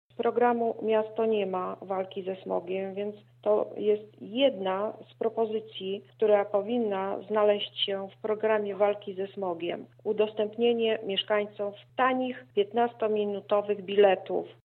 O szczegółach mówi pomysłodawczyni, radna miasta PiS